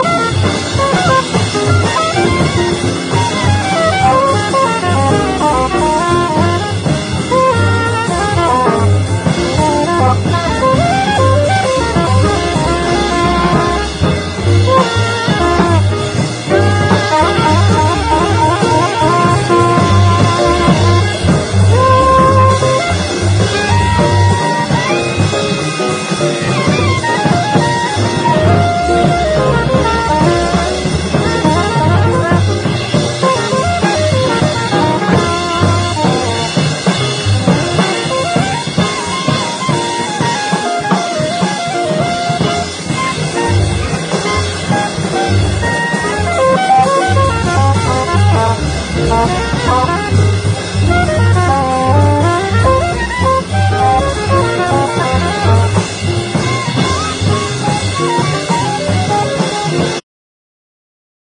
JAZZ / JAZZ VOCAL / VOCAL
ダッチ・ジャズ・ヴォーカルのレア盤！